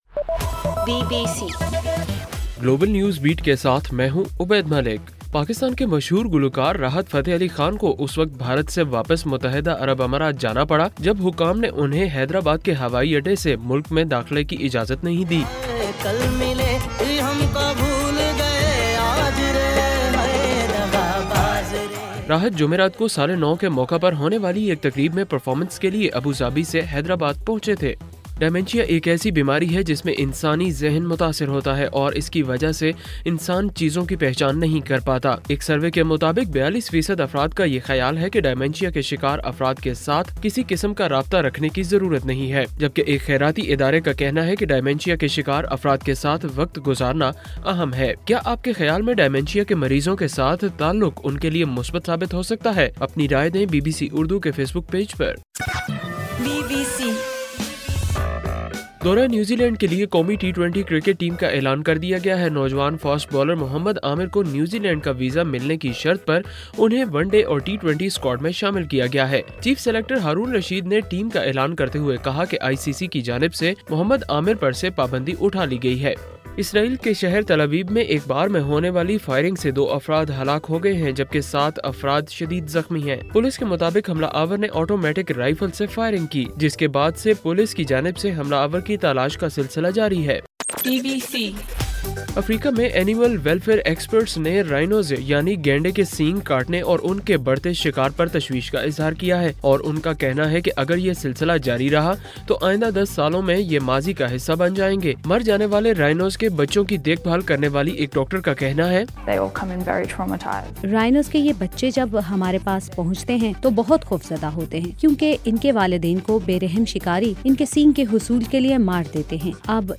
جنوری 01: رات 11 بجے کا گلوبل نیوز بیٹ بُلیٹن